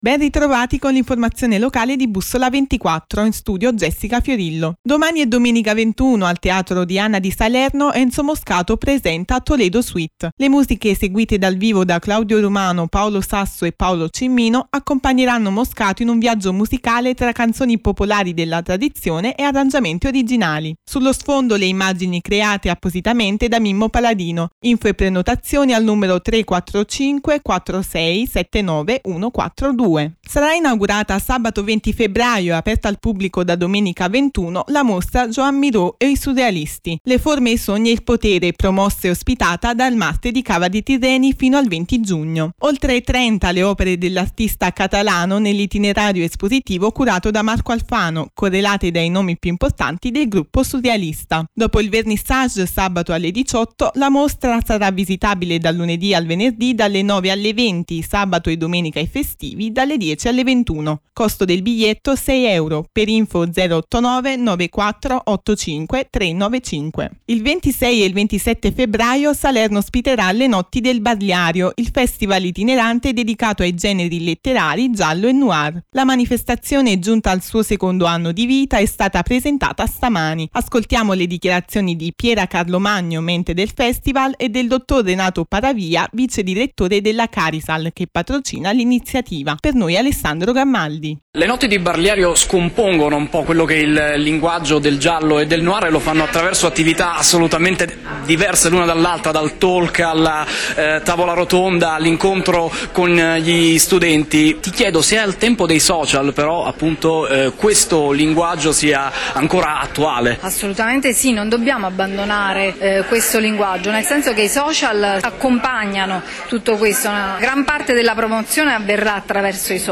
Il 26 e il 27 febbraio, Salerno ospiterà “Le Notti di Barliario”, il festival itinerante dedicato ai generi letterari giallo e noir. La manifestazione, giunta al suo secondo anno di vita, è stata presentata stamani.